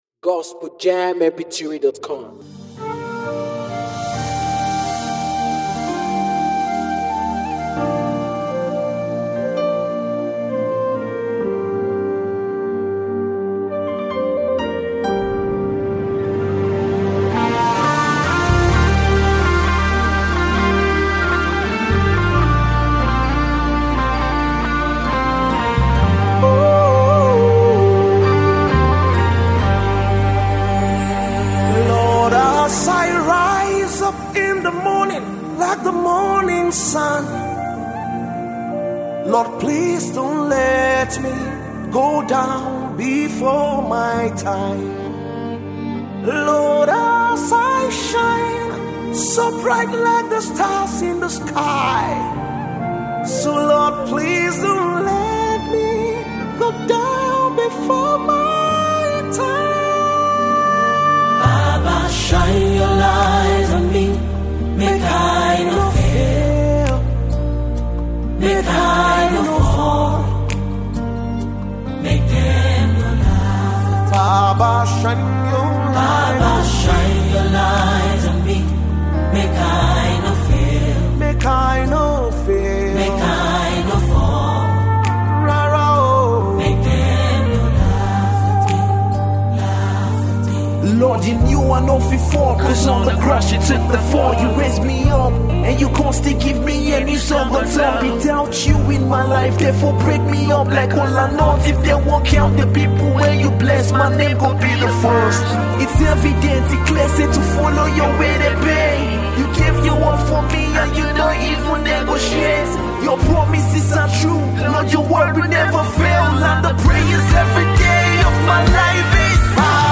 the melodious arrangement